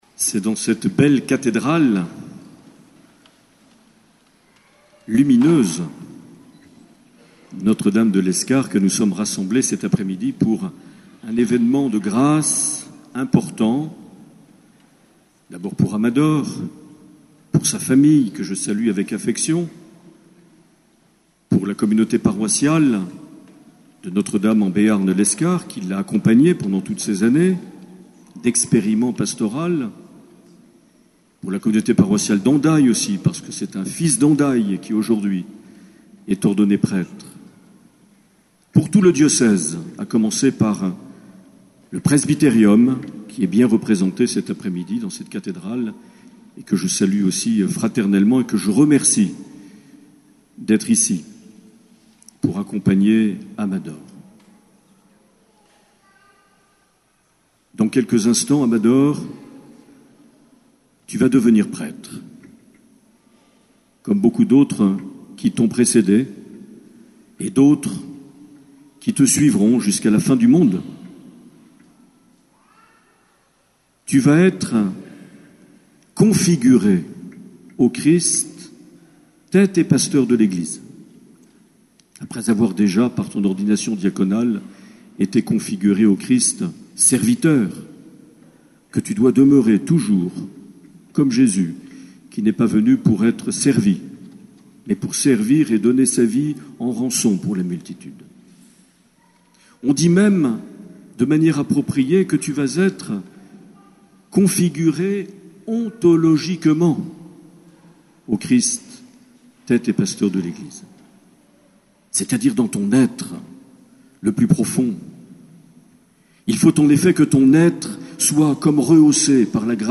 Accueil \ Emissions \ Vie de l’Eglise \ Evêque \ Les Homélies \ 28 juin 2015
Une émission présentée par Monseigneur Marc Aillet